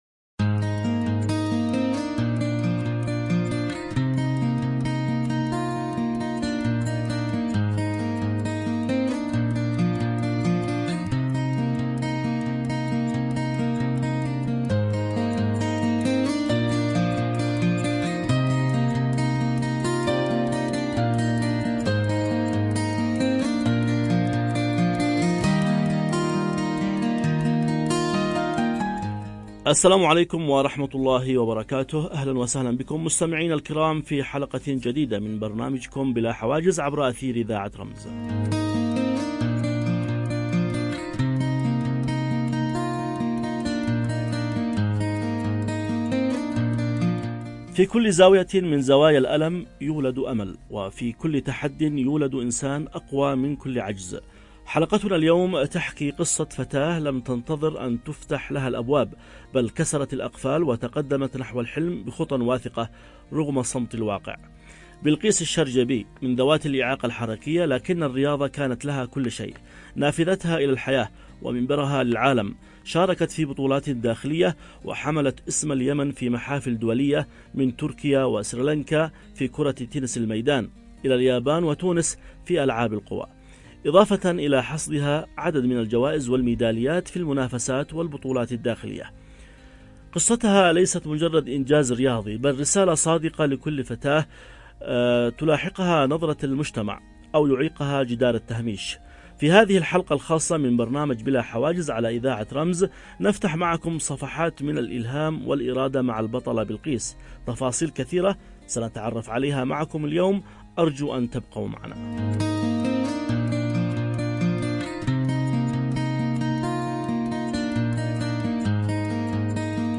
حوار شيق حول تجربتها الشخصية
عبر أثير إذاعة رمز